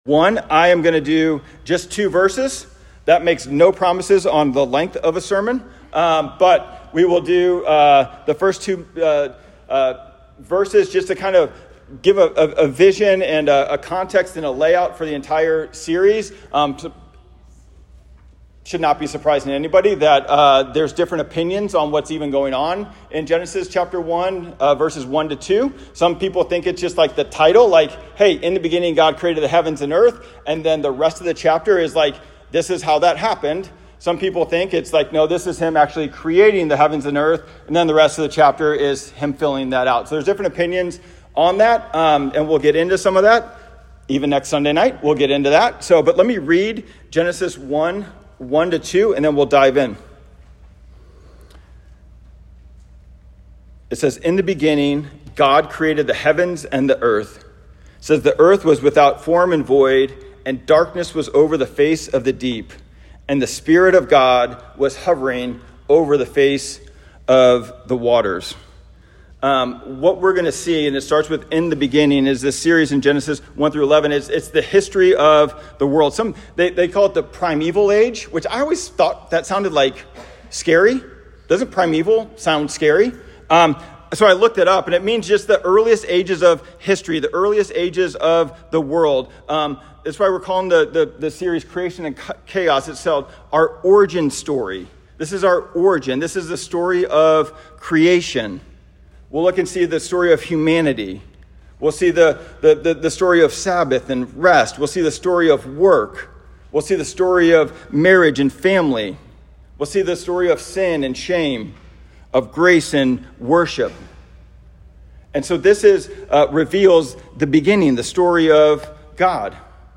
Genesis-1.1-2_Sermon-Only.m4a